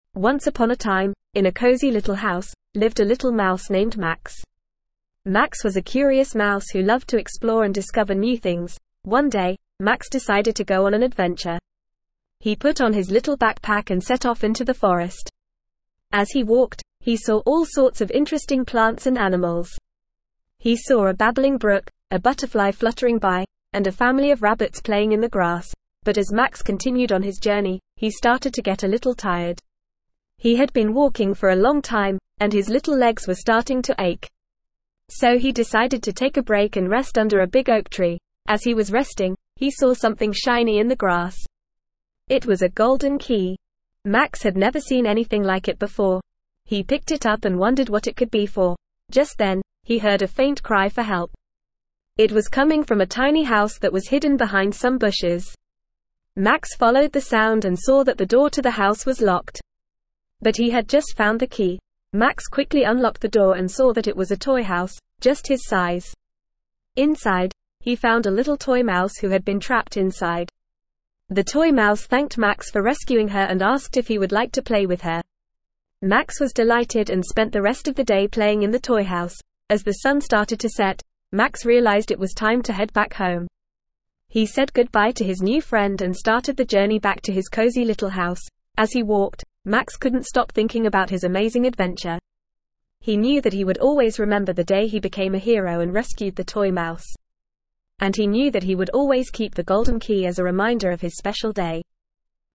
Normal
ESL-Short-Stories-for-Kids-NORMAL-reading-Max-the-Little-Mouse.mp3